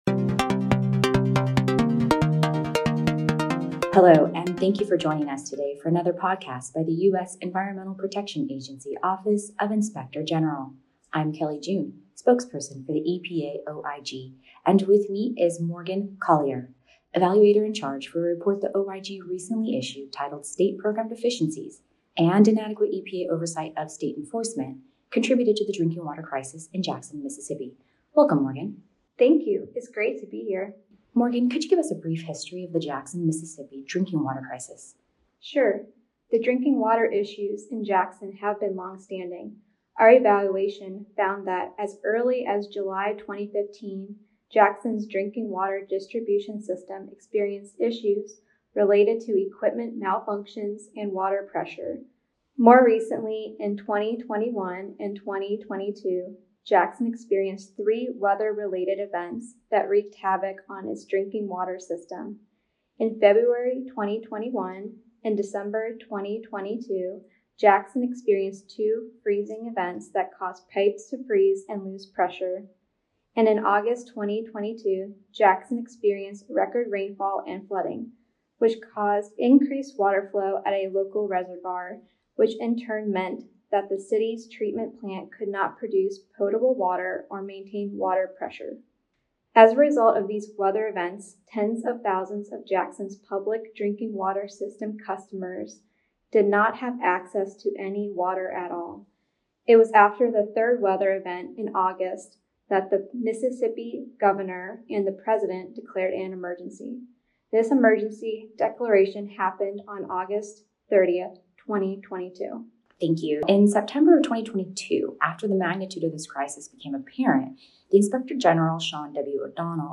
More Podcasts: Listen to our staff talk about their latest audit reports, investigative functions and other initiatives.